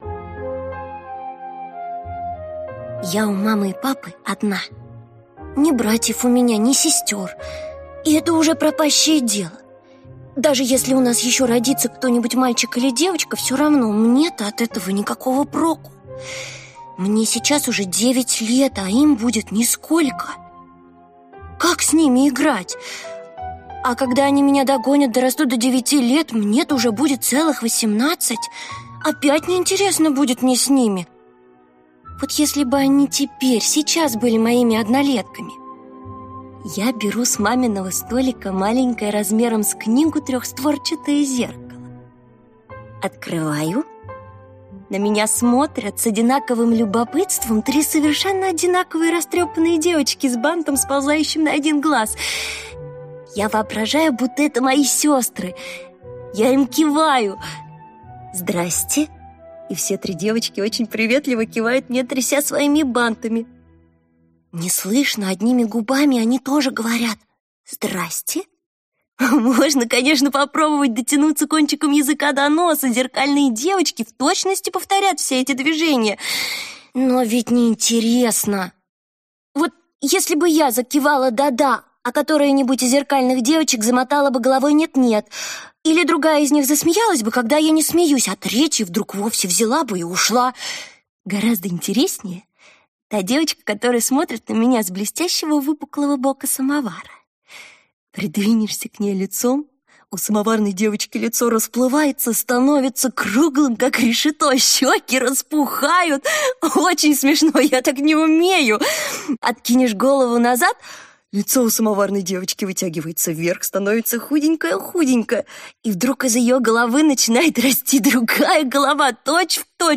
Аудиокнига Дорога уходит в даль…(спектакль) | Библиотека аудиокниг
Aудиокнига Дорога уходит в даль…(спектакль) Автор Александра Бруштейн Читает аудиокнигу Актерский коллектив.